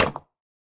Minecraft Version Minecraft Version latest Latest Release | Latest Snapshot latest / assets / minecraft / sounds / block / mud_bricks / break3.ogg Compare With Compare With Latest Release | Latest Snapshot